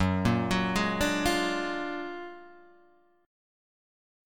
Gb+M9 Chord